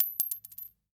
household
Coin Dime Dropping on Cement 2